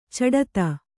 ♪ caḍata